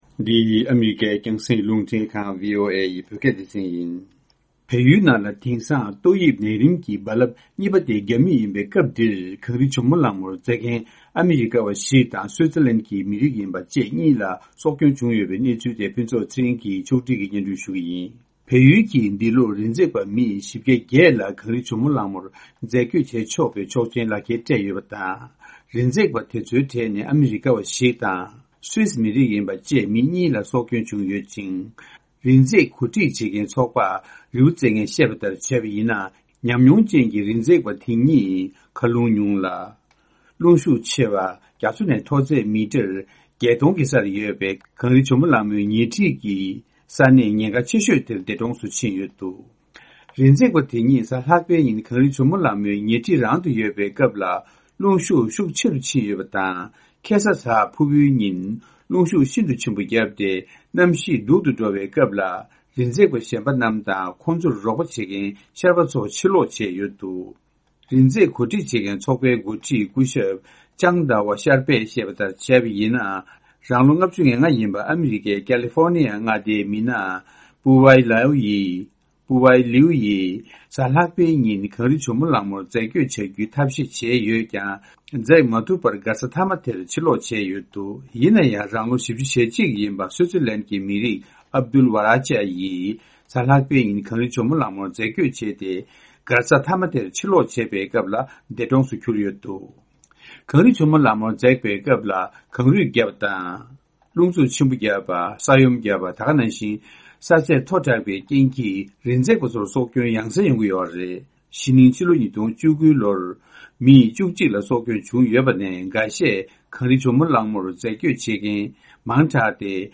ཕྱོགས་བསྒྲིགས་ཀྱིས་སྙན་སྒྲོན་ཞུ་ཡི་རེད།།